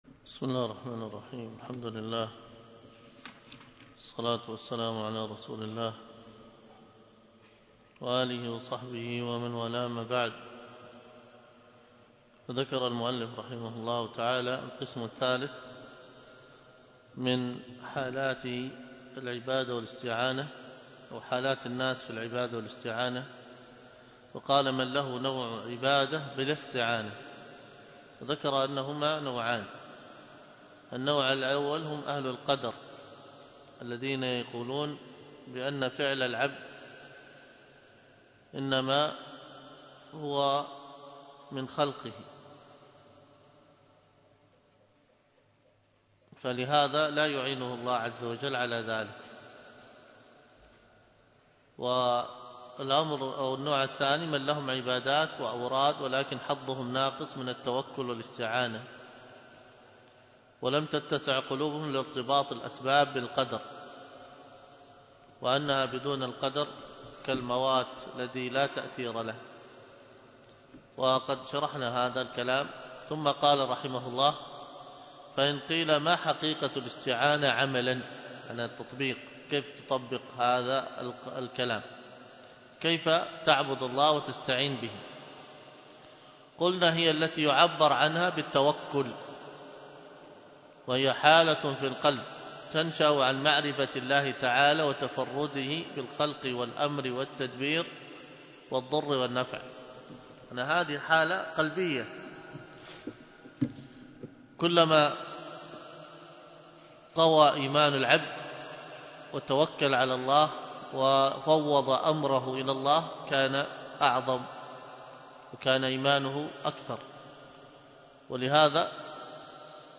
الدرس في كتاب الحج 10، ألقاها